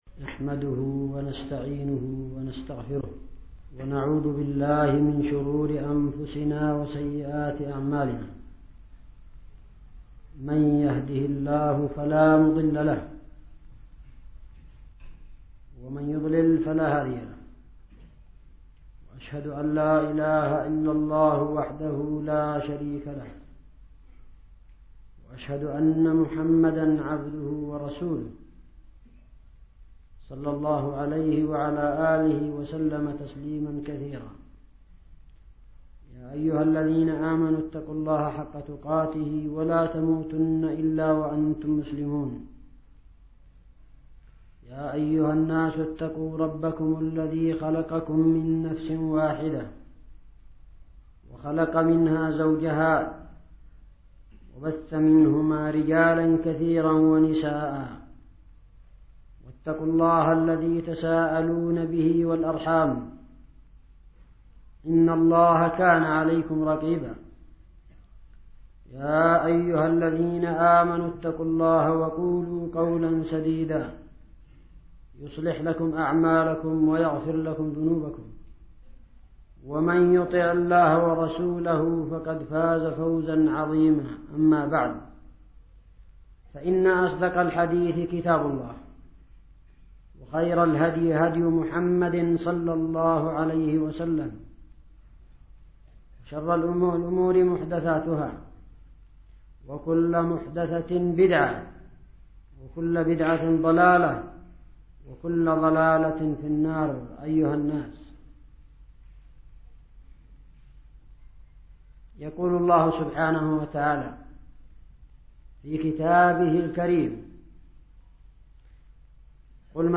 خطبة جمعة حول: الصبر